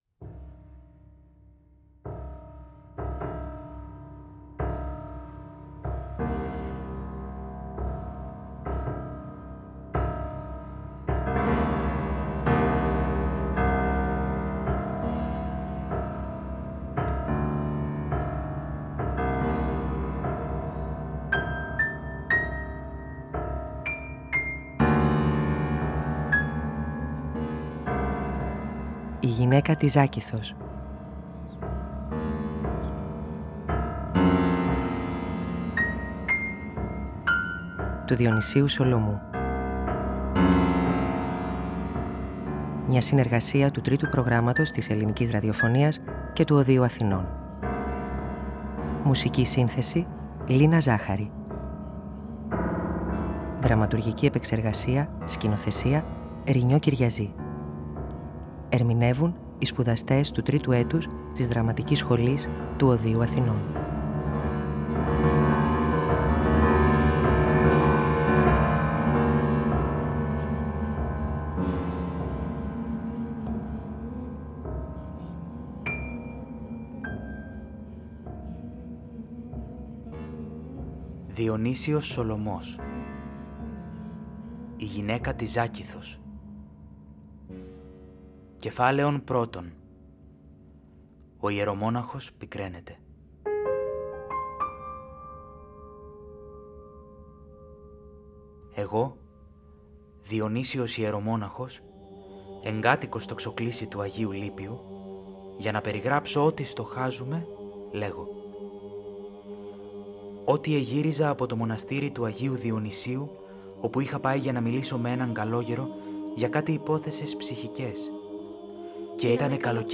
Τρίτο Πρόγραμμα & Ωδείο Αθηνών συνεργάζονται σε μια πρότυπη ραδιοφωνική θεατρική παραγωγή, υλοποιώντας για 2η χρονιά την πρόθεση σκέψη & επιθυμία για μια μόνιμη συνεργασία στο Ραδιοφωνικό Θέατρο.
Οι σπουδαστές του Τρίτου Έτους της Δραματικής Σχολής του Ωδείου Αθηνών του παλαιότερου εκπαιδευτικού οργανισμού της χώρας για τη Μουσική και το Θέατρο (1871) συναντήθηκαν στα studio του Τρίτου Προγράμματος και δημιούργησαν το δεύτερο ραδιοφωνικό θεατρικό έργο. Πρόκειται για το έργο «Η Γυναίκα της Ζάκυθος» του Διονυσίου Σολωμού.